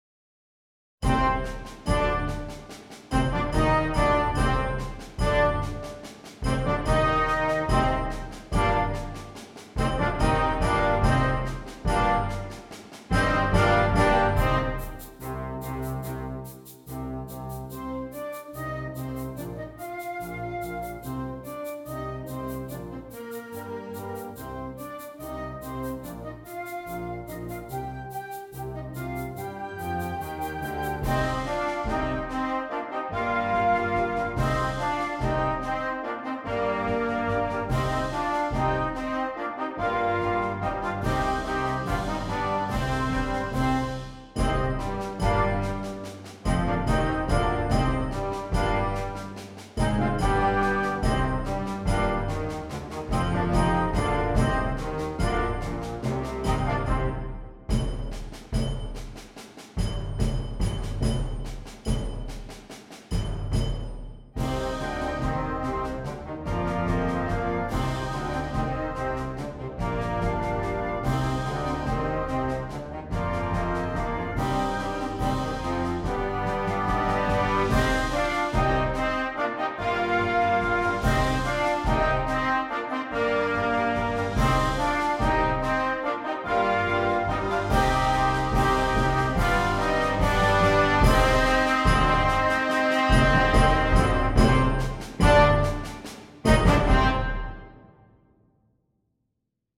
Concert Band